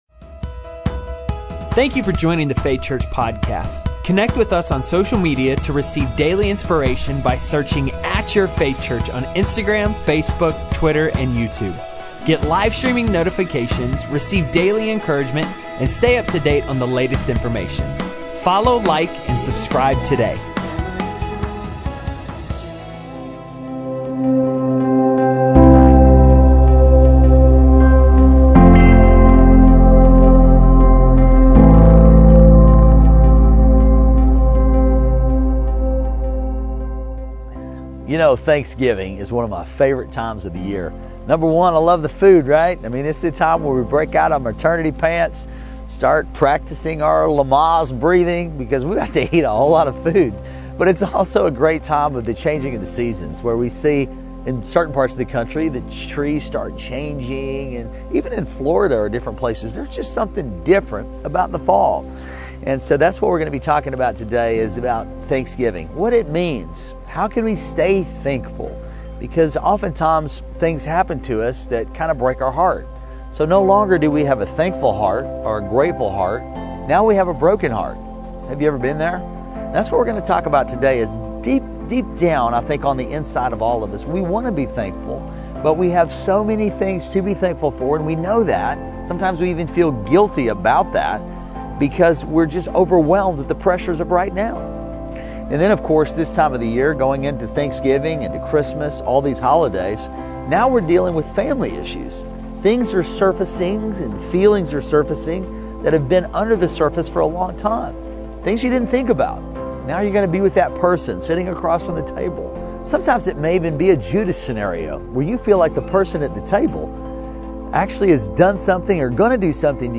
Faith Church Audio Podcast